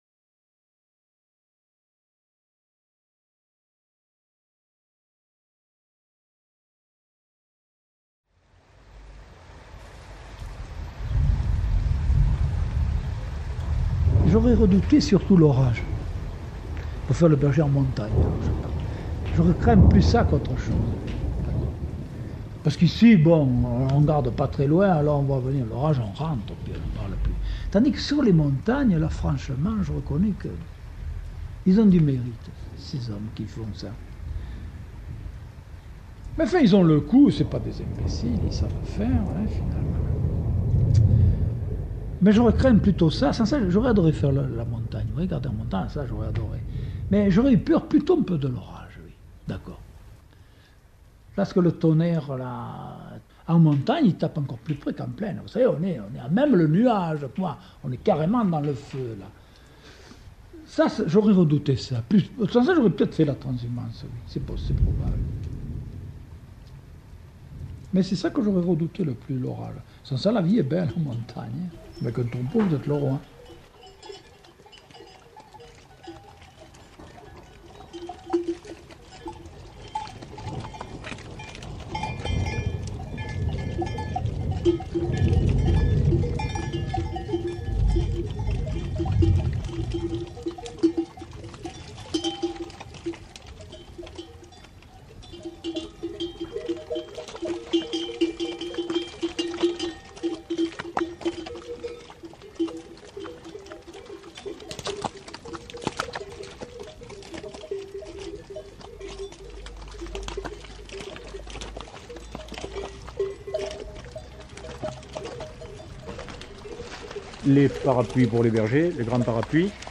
Get £1.30 by recommending this book 🛈 Suivons dans l'odeur du thym et des lavandes, un troupeau de moutons préalpes du sud qui monte vers l'estive : bouc, ânes et border-collies en tête. Bergers, éleveurs, femmes et enfants, fabricant de sonnailles et de parapluie... tout le petit monde de la transhumance est là avec l'assent pour vous dire leur pays et leur passion.